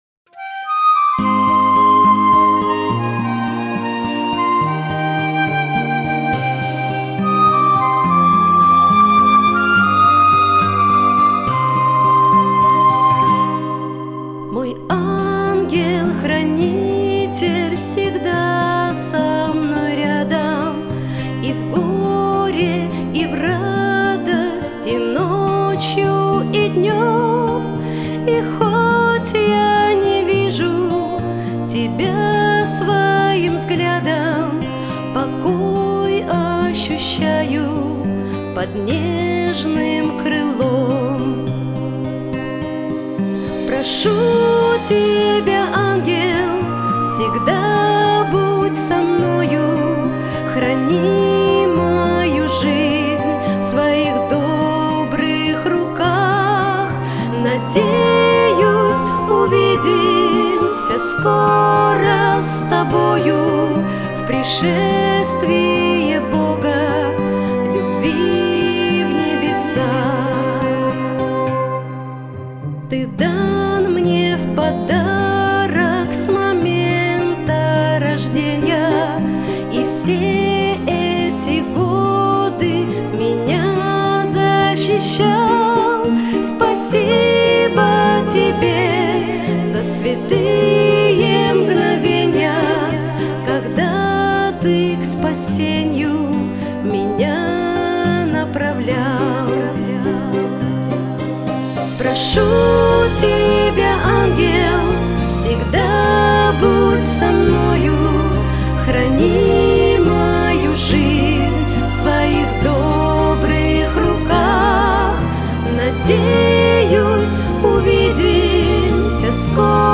песня
1004 просмотра 648 прослушиваний 97 скачиваний BPM: 75